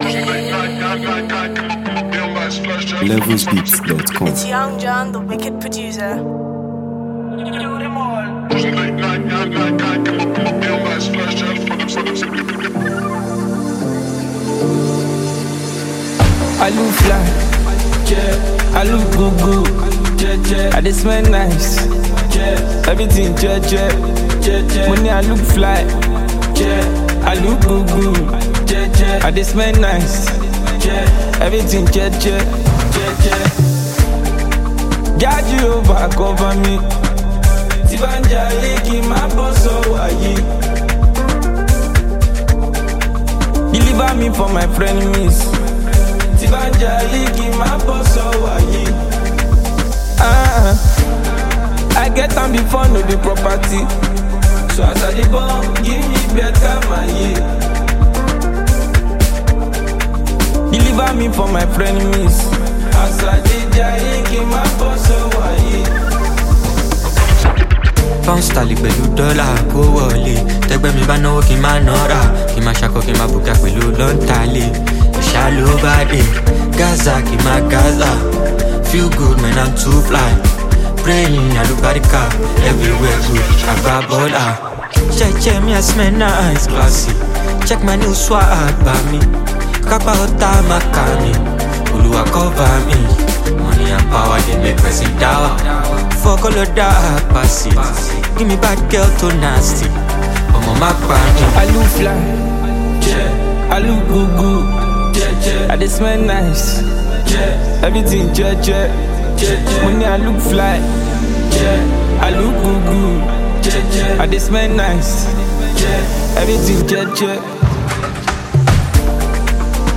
vibrant Afrobeats song